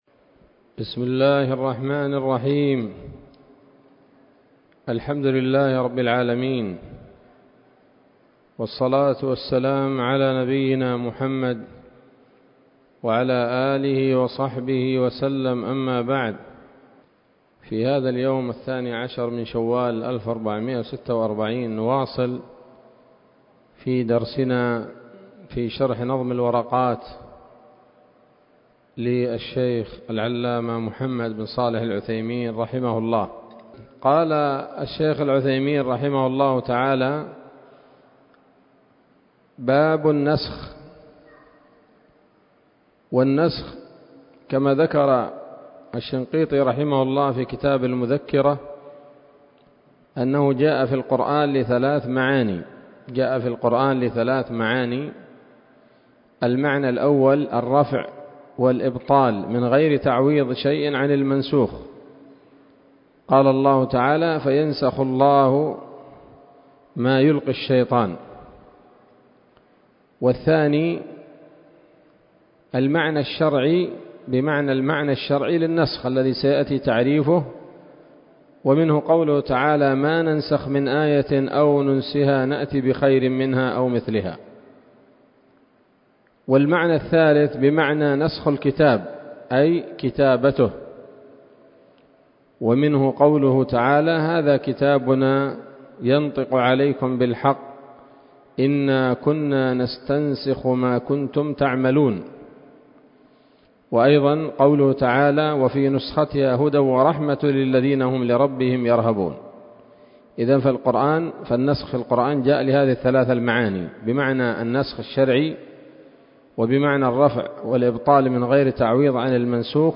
الدرس الثامن والأربعون من شرح نظم الورقات للعلامة العثيمين رحمه الله تعالى